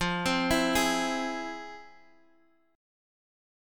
FmM7 Chord